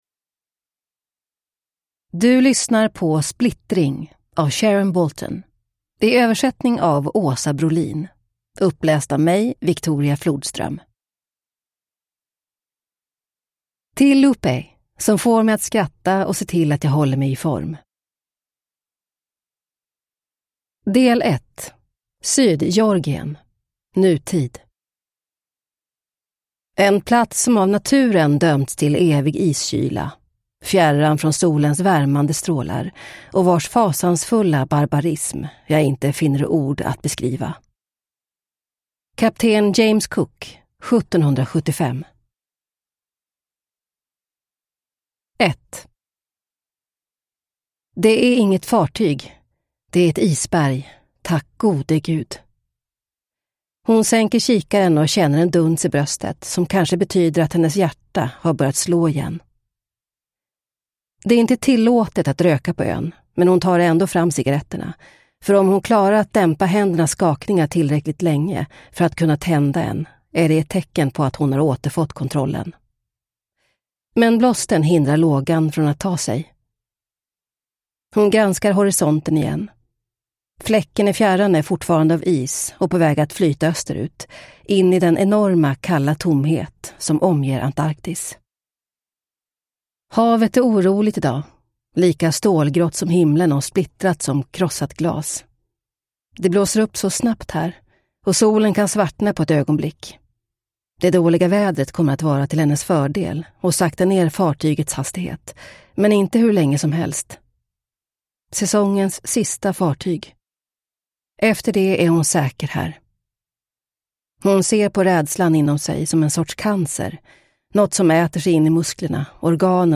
Splittring (ljudbok) av Sharon Bolton